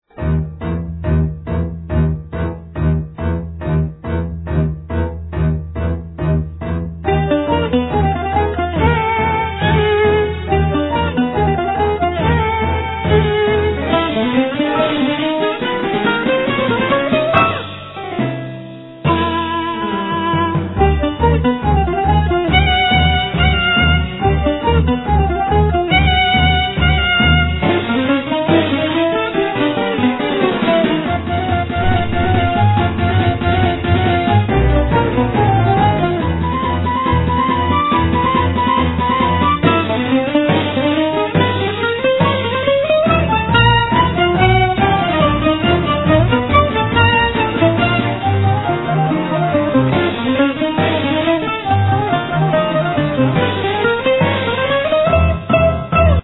Sevi, Doudouk
Violin, Viola
Acoustic bass
Drams, Percussions
Bouzouki
Piano, Orchestration, Programming